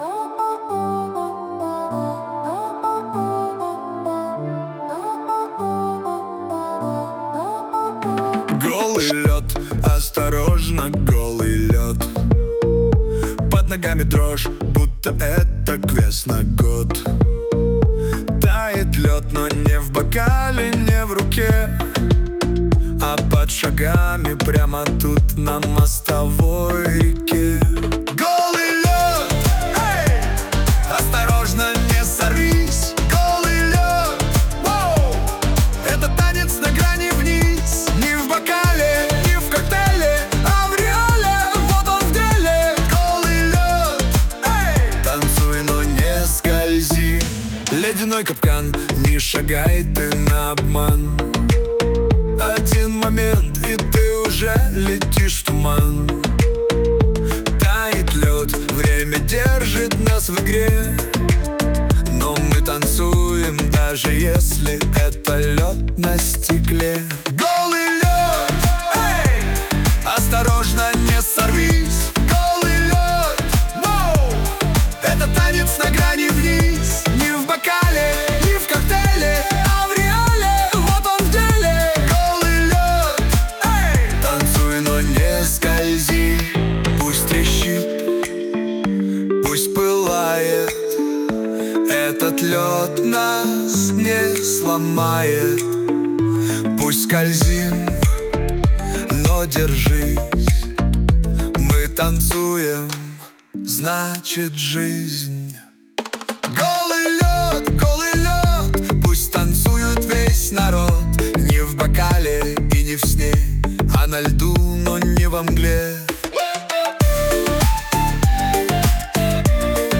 RUS, Romantic, Dance, Pop, Disco | 16.03.2025 11:03